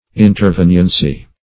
Search Result for " interveniency" : The Collaborative International Dictionary of English v.0.48: Intervenience \In`ter*ven"ience\, Interveniency \In`ter*ven"ien*cy\, n. Intervention; interposition.